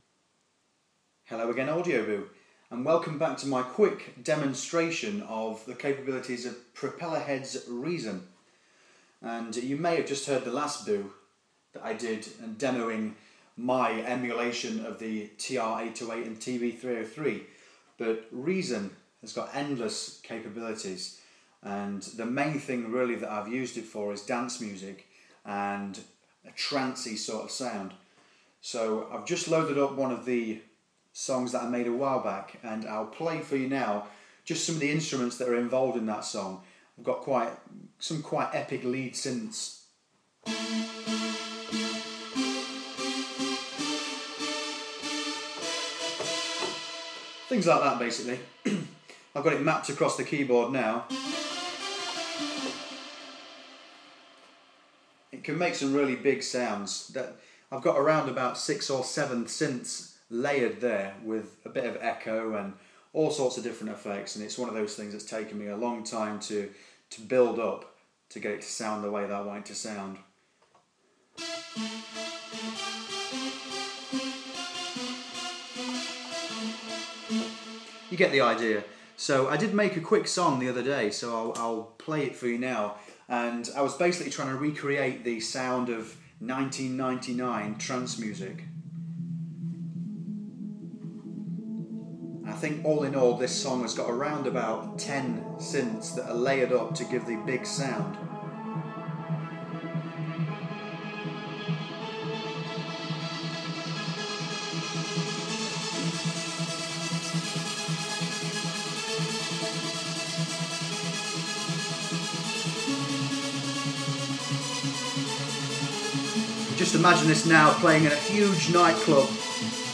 In the second instalment of my Propellerheads Reason Boo I give you a demo of a bit of Trance, Acid Bass, Vocoder and play an 80's riff on a Moog synth patch